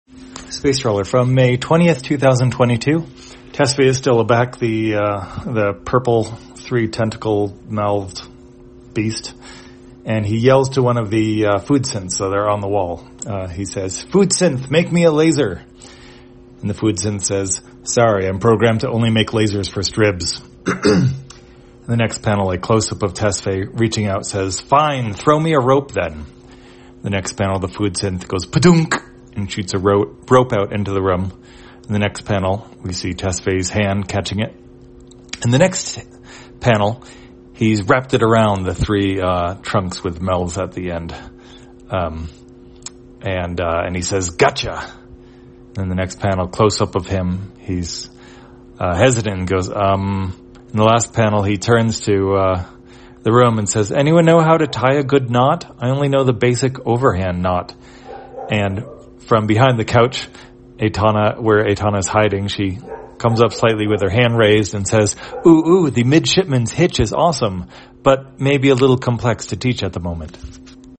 Spacetrawler, audio version For the blind or visually impaired, May 20, 2022.